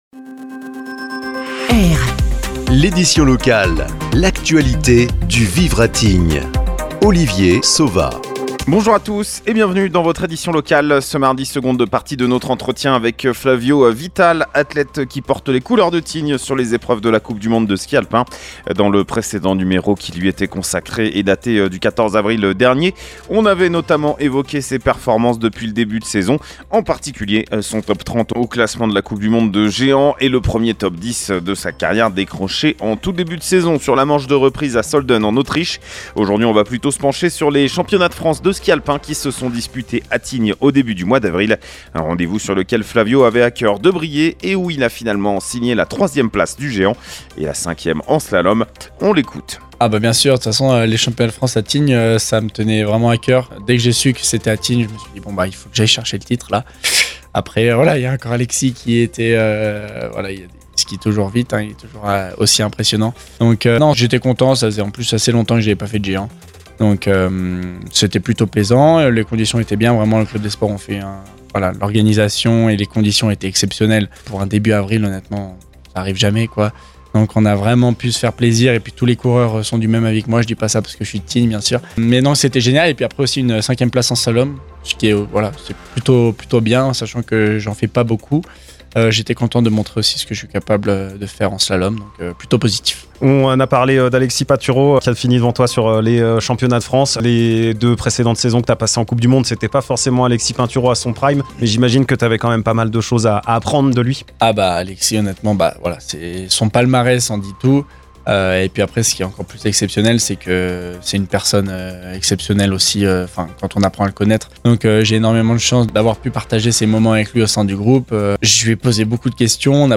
AU SOMMAIRE DE L’ÉDITION LOCALE